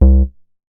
MoogDubba 003.WAV